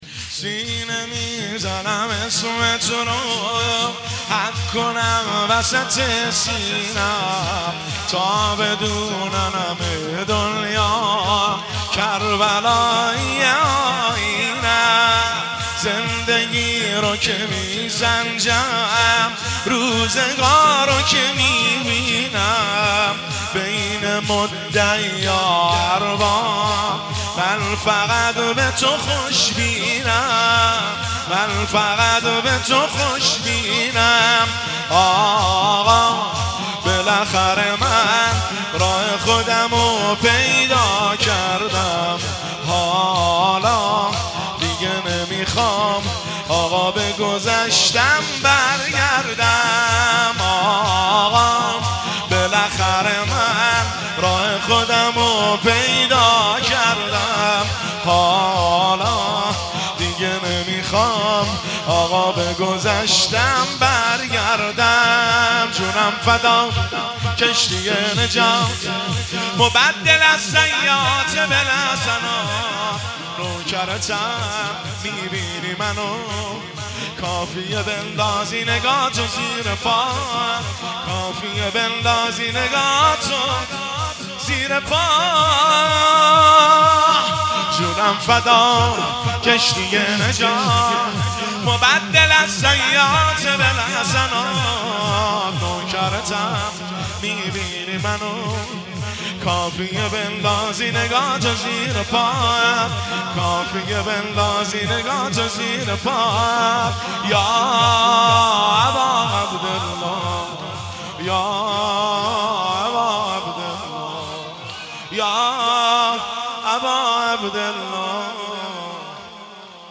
مداحی شور
سینه میزنم اسمت رو حک کنم وسط سینم - جلسه هفتگی 12 دی 1403 - هیئت بین الحرمین طهران